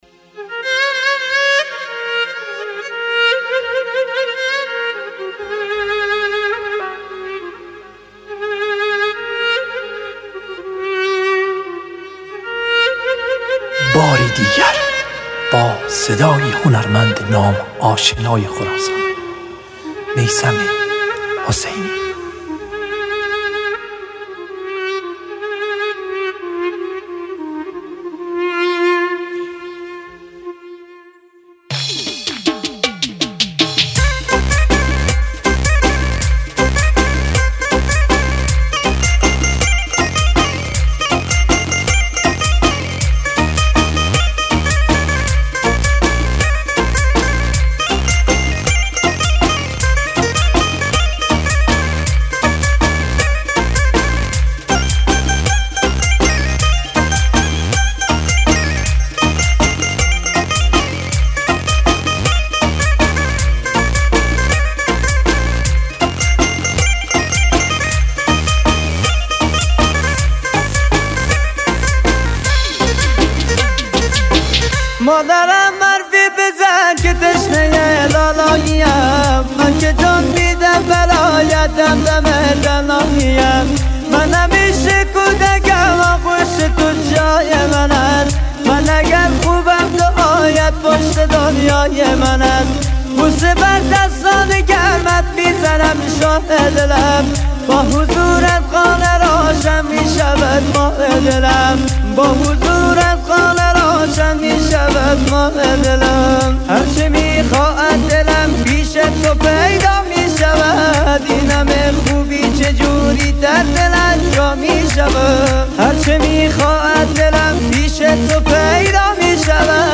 اهنگ احساسی و وفق العاده
با صدای مرد